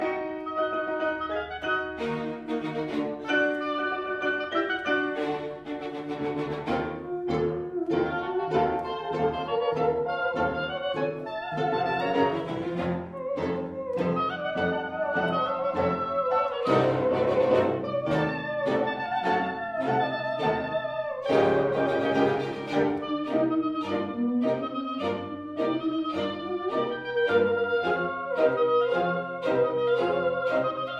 The musical excerpt below is from a septet (a piece for seven performers). Five of the instruments heard are strings (two violins, viola, cello) and piano.